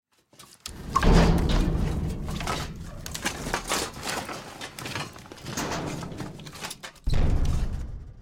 waste_container_1.ogg